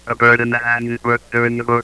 Speech synthesis demo
Formants 1, 2 and 3 (parameters 2 - 7 above)
Bird(F1F2F3).aiff